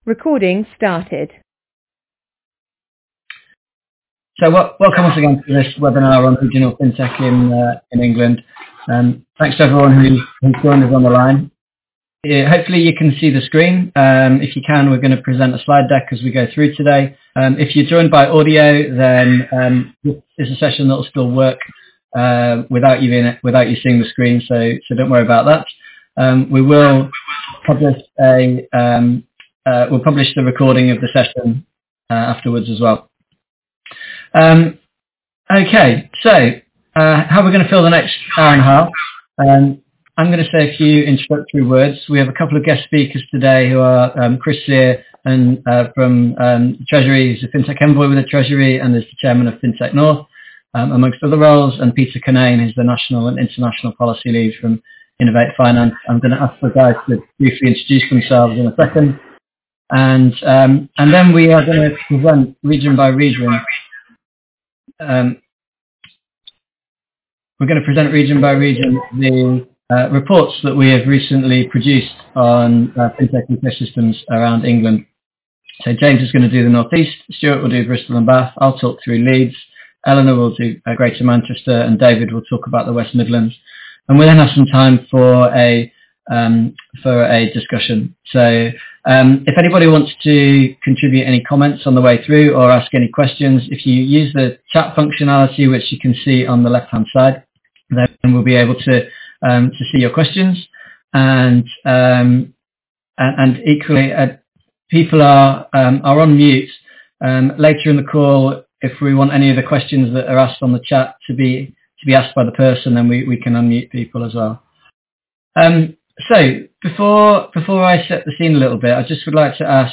Regional-FinTech-in-England-Webinar-24-4-20-audio-recording.mp3